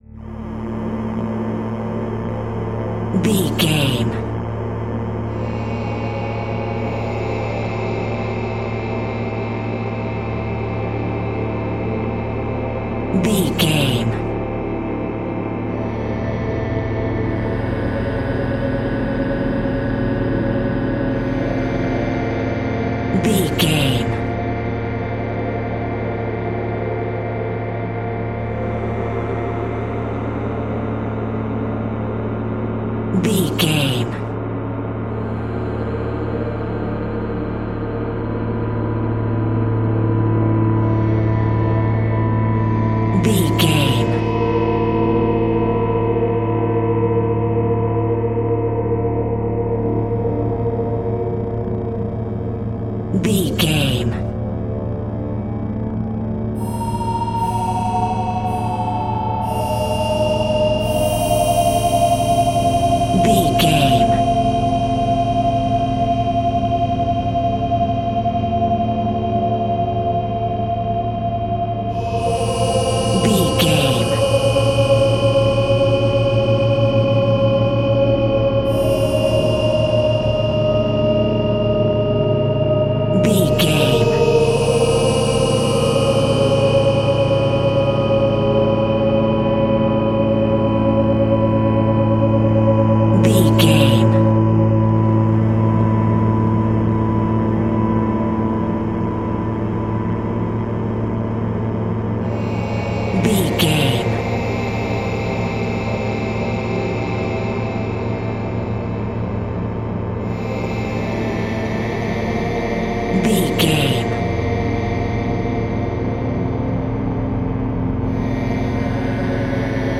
Atonal
Slow
scary
tension
ominous
dark
eerie
synthesiser
keyboards
ambience
pads